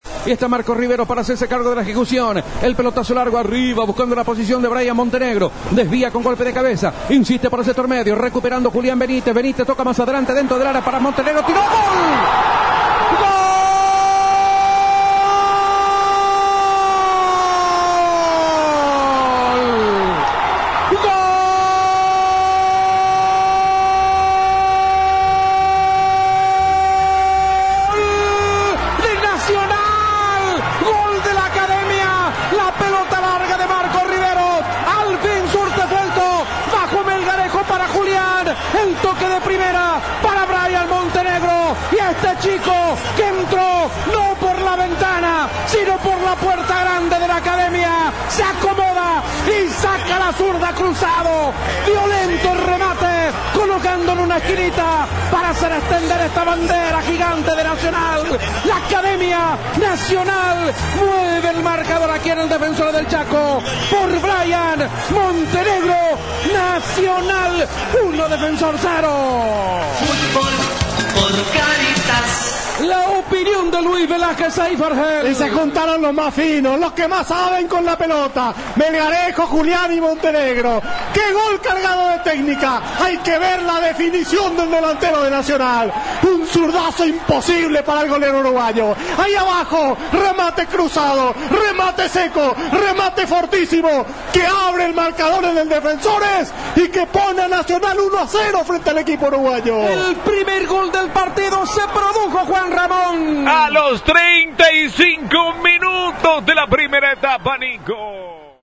1.-goool-de-nacional-montenegro.mp3